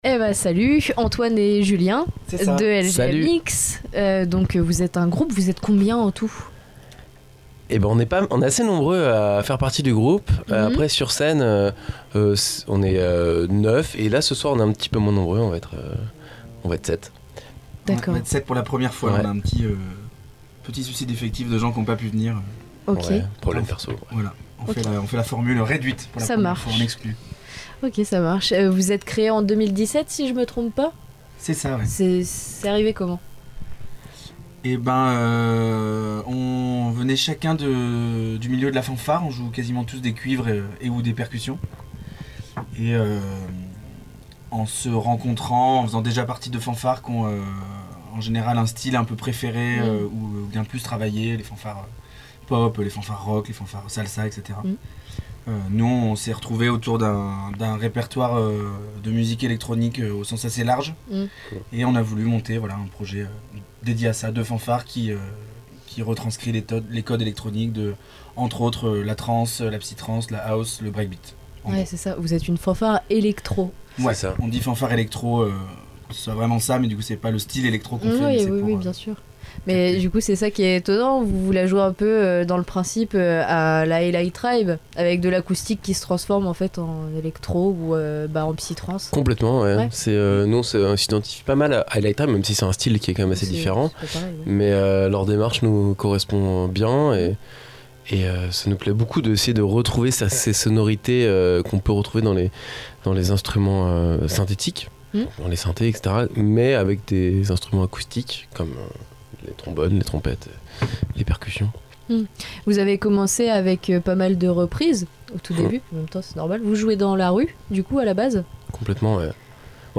Interviews RCDF En Fanfare !